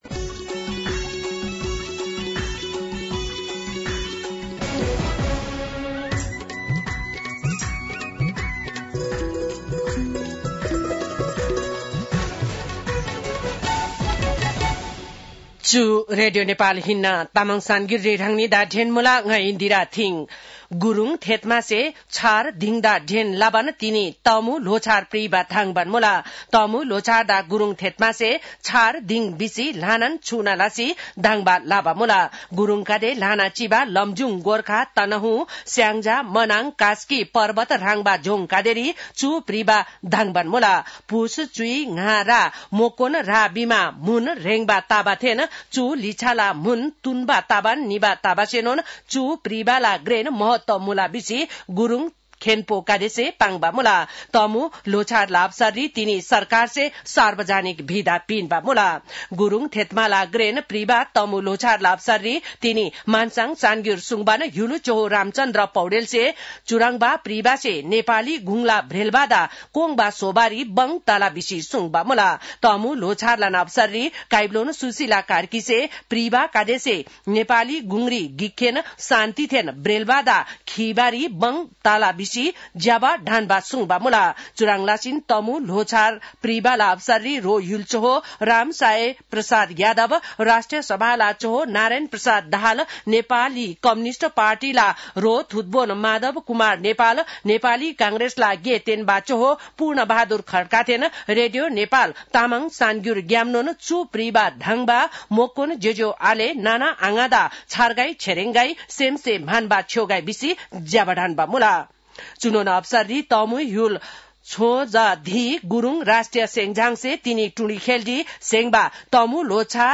तामाङ भाषाको समाचार : १५ पुष , २०८२